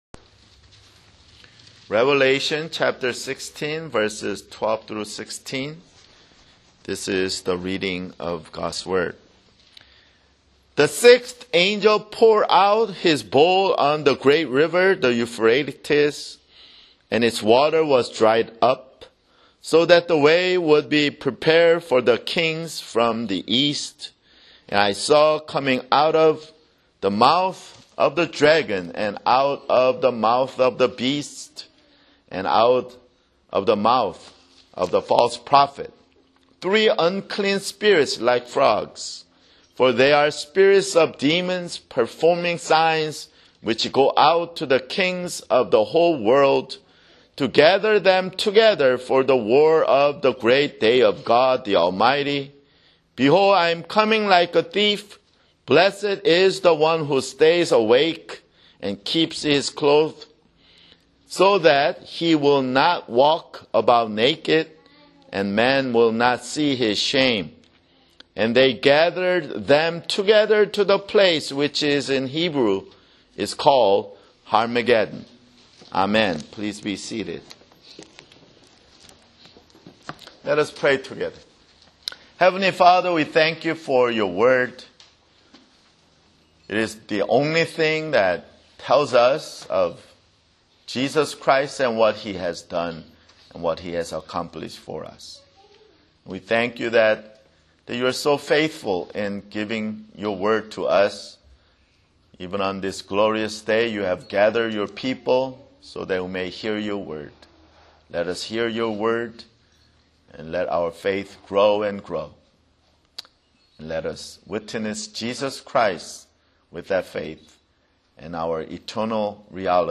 [Sermon] Revelation (62)